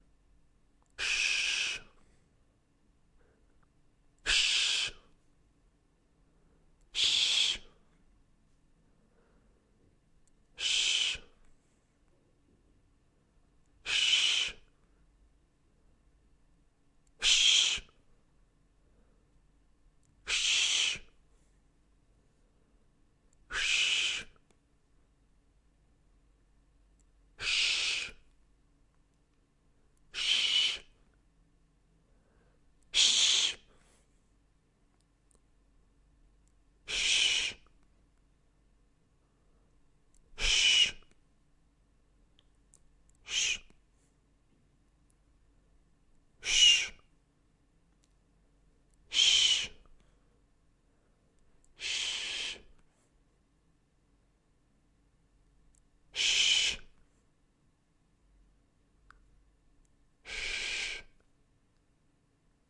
男性 Shh
用Zoom H4n Pro和Audio Technica AT2020录制。
Tag: 声音 男性